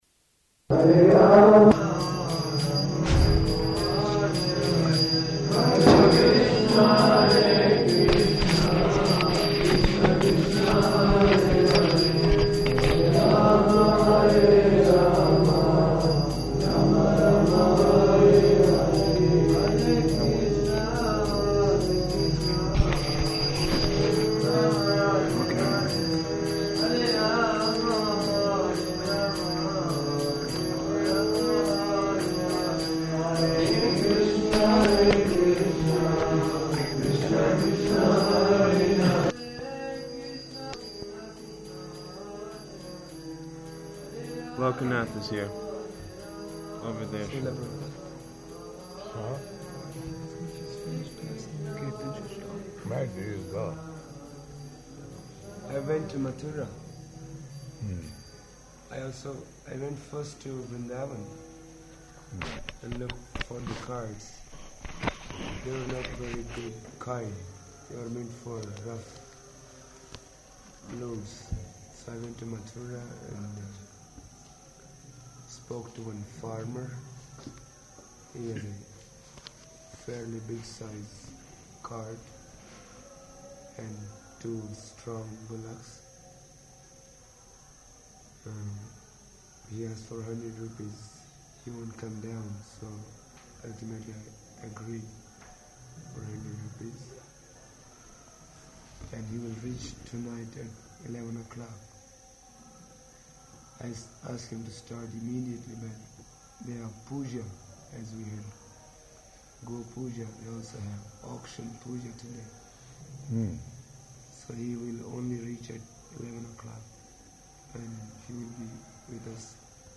Room Conversation
-- Type: Conversation Dated: November 10th 1977 Location: Vṛndāvana Audio file